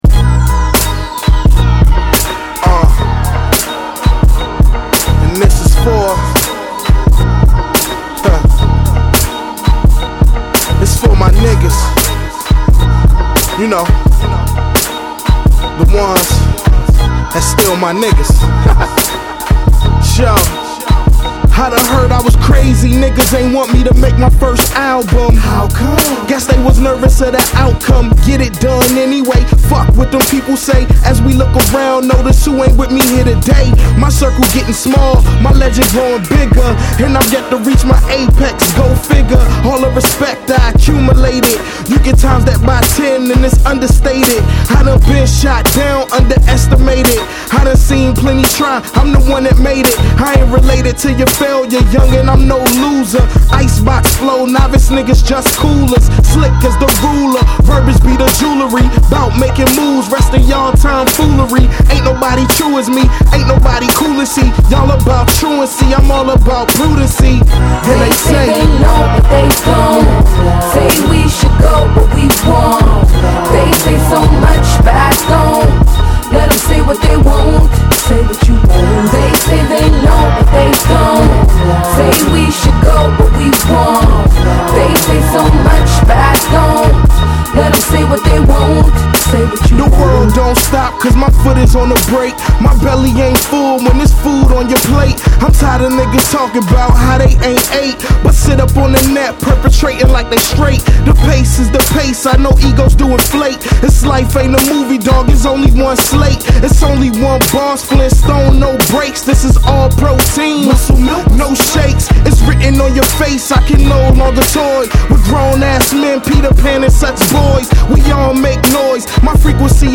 a nice mix of heavy bass, synths and vocal chops